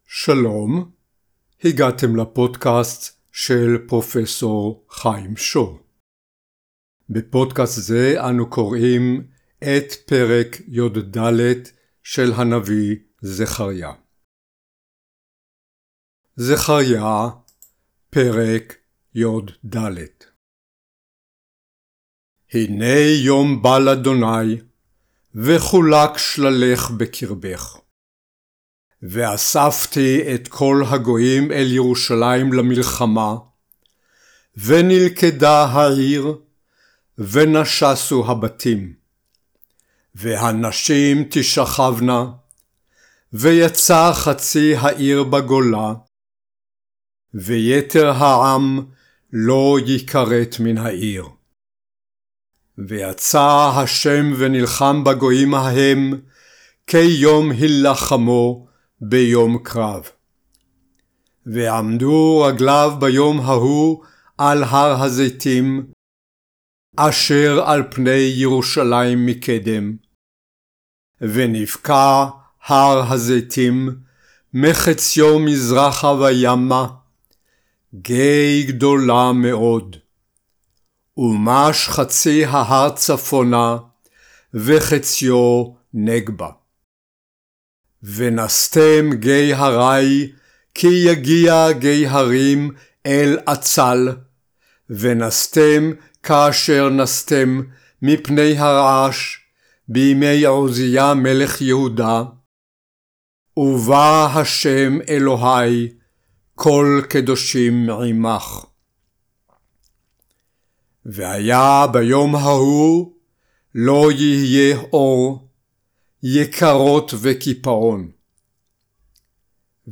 Reading Bible Prophets (Zechariah, Ch. 14; Hebrew; Hebrew/English text; Post/Podcast; Enhanced Audio)